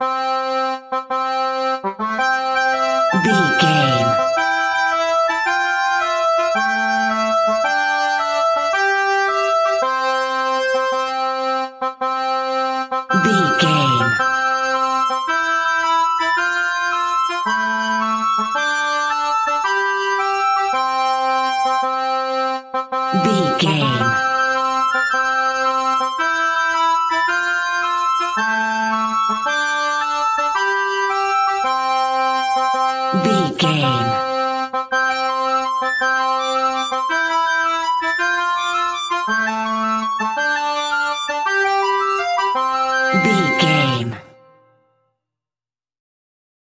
Uplifting
Ionian/Major
nursery rhymes
kids music